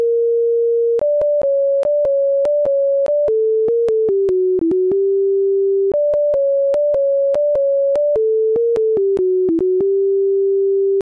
To demonstrate how the metrics can be used to compare tunes, we use as our example a dancing tune from the 17th century called Devil’s Dream, found in the 1657 and 1670 versions of the Dancing Master, compiled by John Playford.